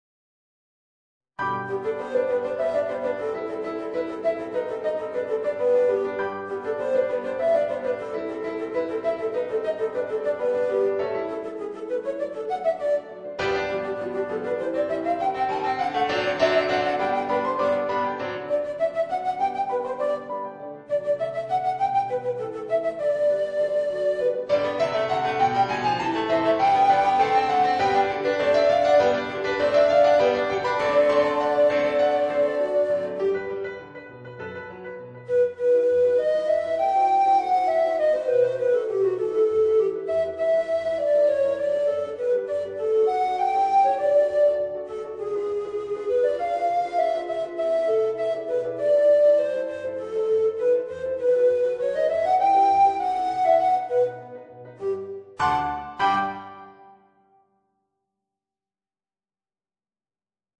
Voicing: Tenor Recorder and Organ